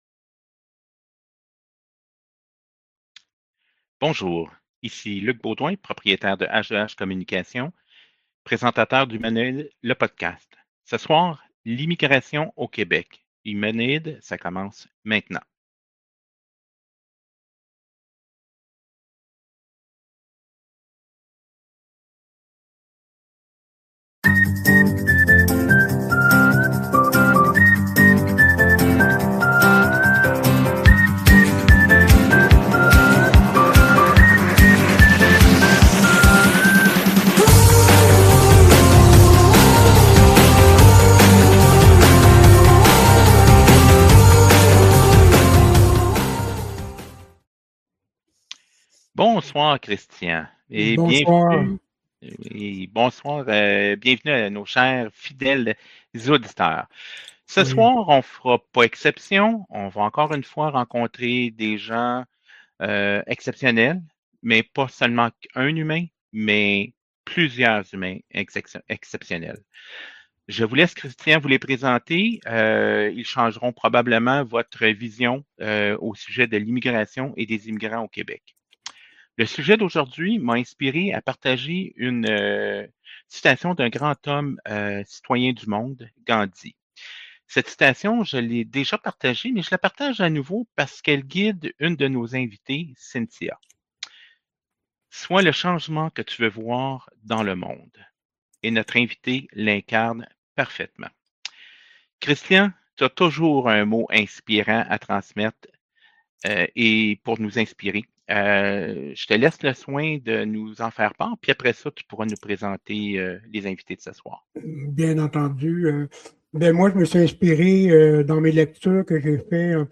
Dans cet épisode, on discute d’immigration au Québec avec nos trois invités.